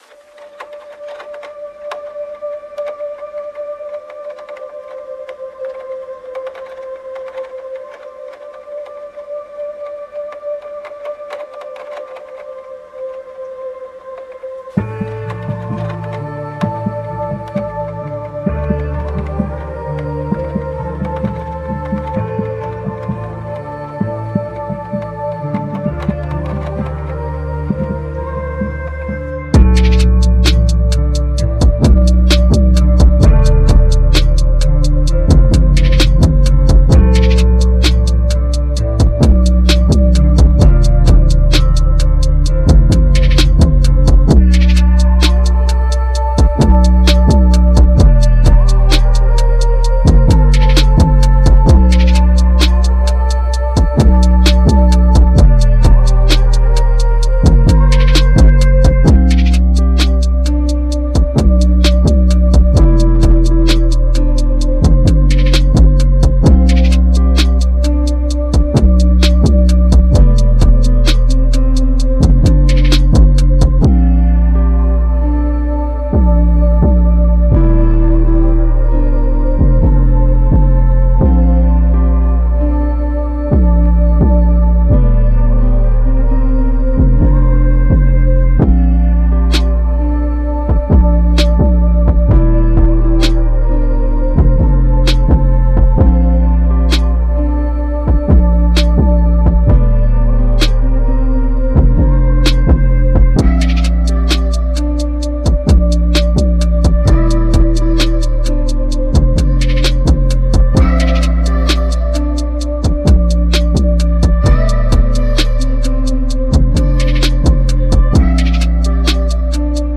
Hip-Hop هیپ هاپ